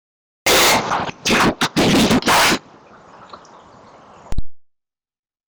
Electronic Voice Phenomenon Lydklipp er kommet!
MEN! på det neste opptaket (Gjort ved en grav som var over 160 år gammel) fikk jeg den mest sinte, onde, og mørke stemmen jeg noen gang har hørt(og forstått!)
Merk; Den er IKKE redigert på noen måte, annet en at jeg har tatt med bare EVP biten.